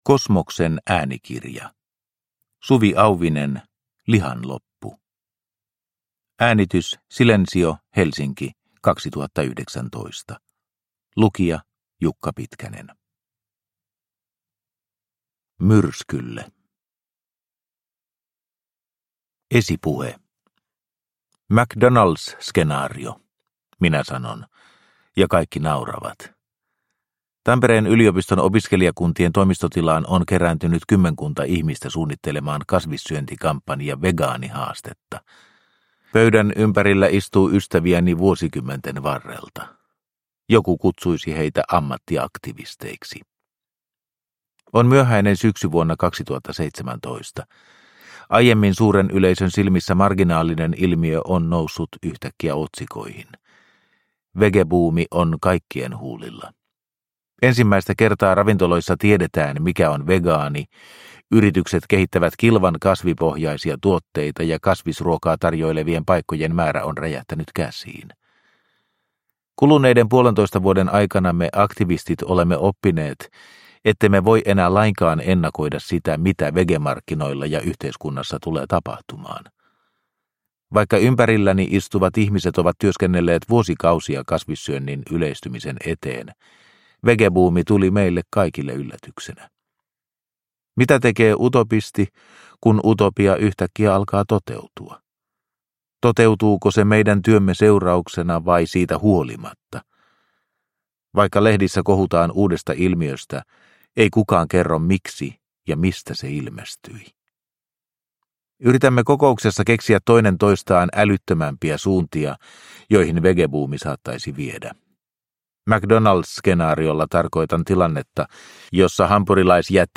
Lihan loppu – Ljudbok – Laddas ner